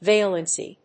音節va・len・cy 発音記号・読み方
/véɪlənsi(米国英語)/